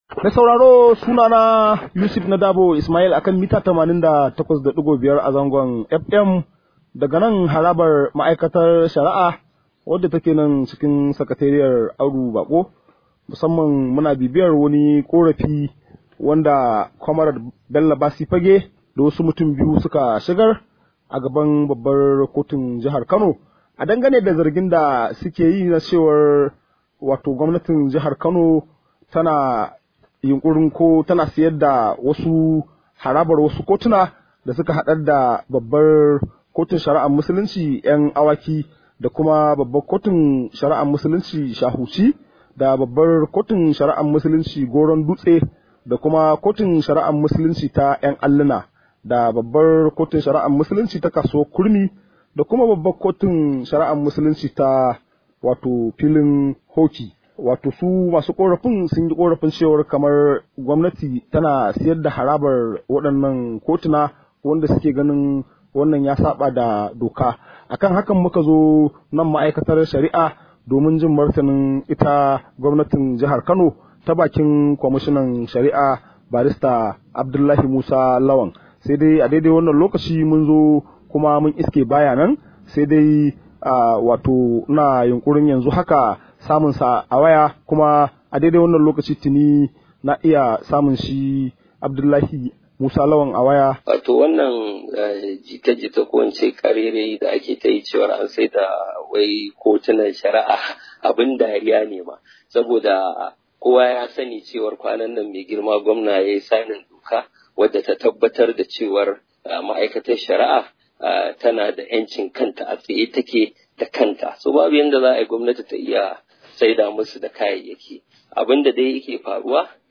Rahoto: Gwamnatin Kano ba ta sayar da harabar kotunan ta ba – Kwamishinan shari’a